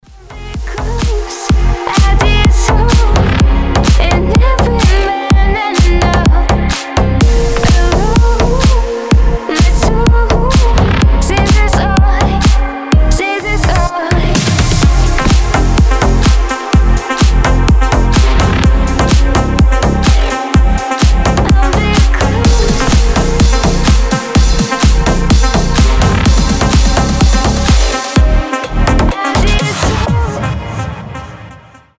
• Качество: 320, Stereo
deep house
красивый женский голос